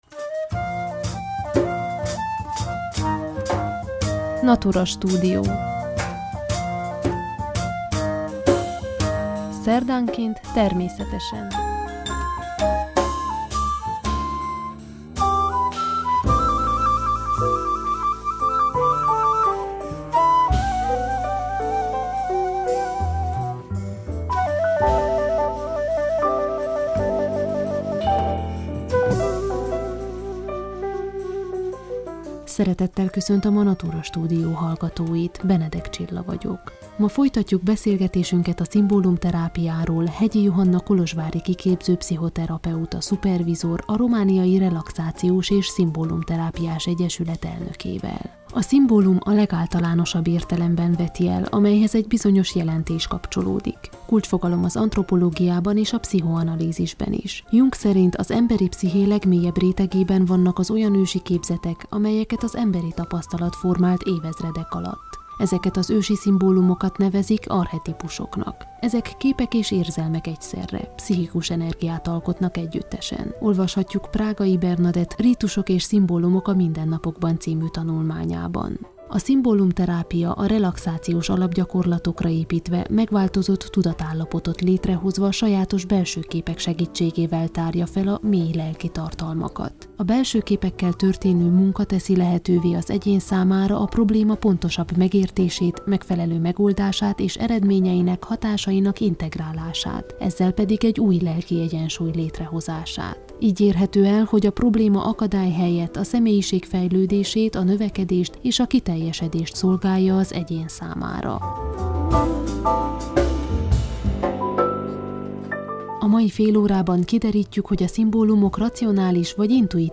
Mai adásunkban folytatjuk beszélgetésünket a szimbólumterápiáról